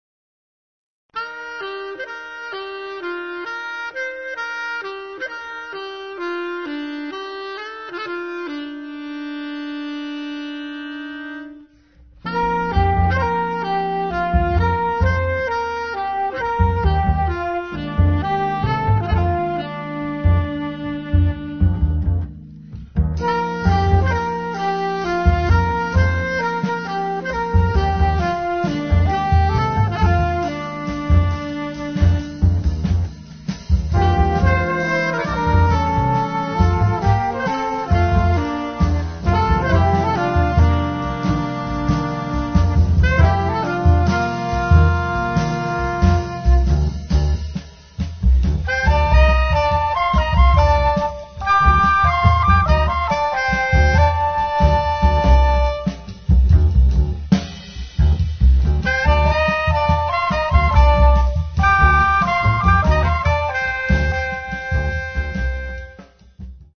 flauto & alto flauto
tromba & flicorno
alto sax & clarinetto basso
corno francese
piano & piano elettrico
contrabbasso
batteria